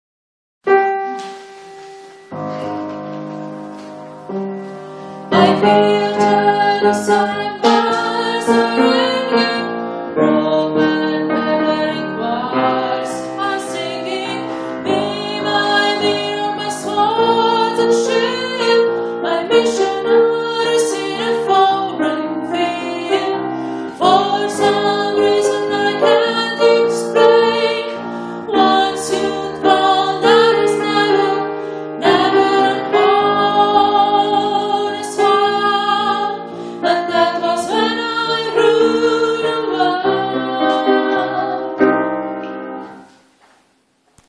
Viva la Vida – Bass-Refrain
Viva-La-Vida-Refrain-Bass.mp3.mp3